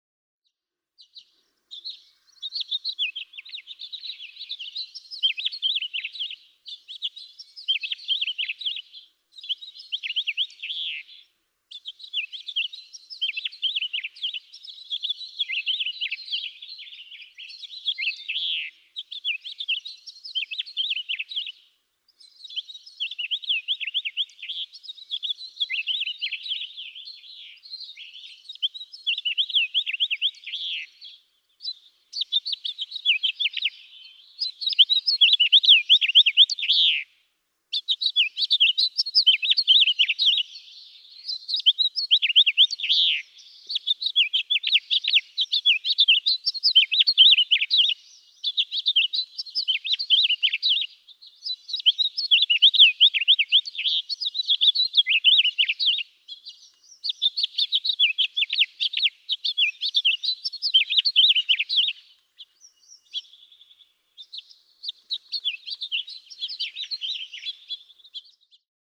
House finch
Energized singing at dawn. There's a second singing house finch in the background.
Anza-Borrego Desert State Park, California.
092_House_Finch.mp3